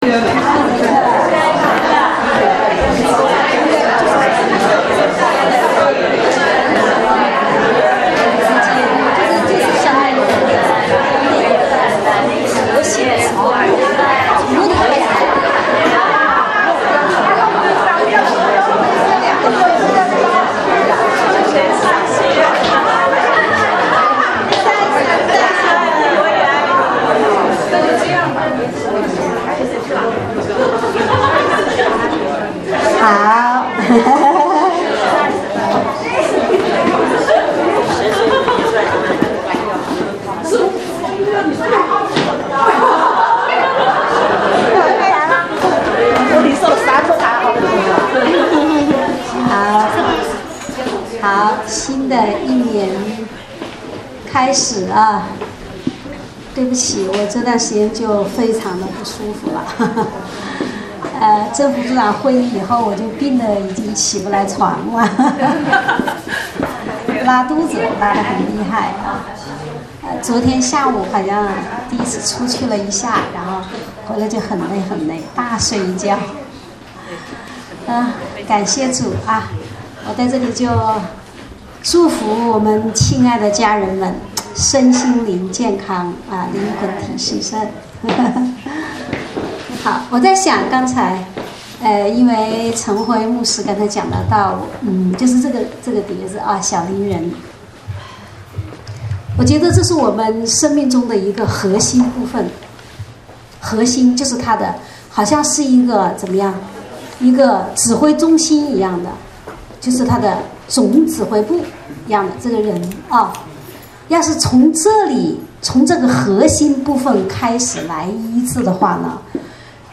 正在播放：--主日恩膏聚会录音（2015-01-04）